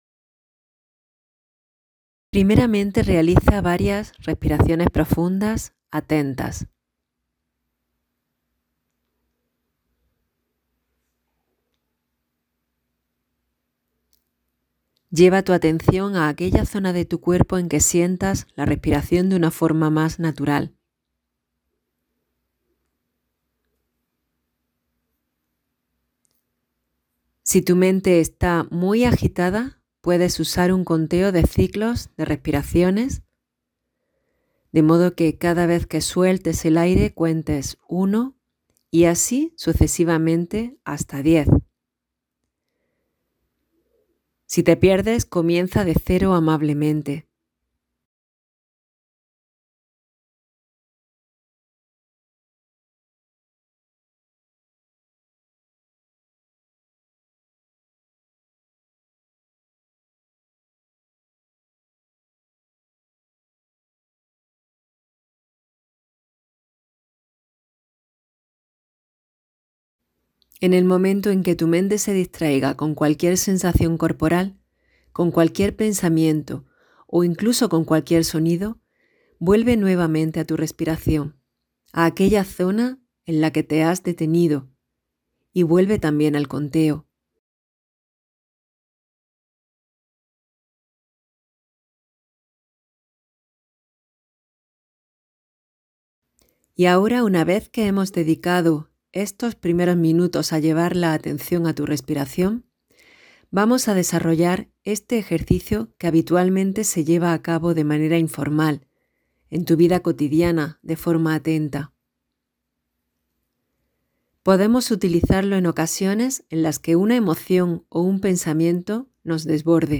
Meditación de la técnica RAIN para gestión de emociones desagradables: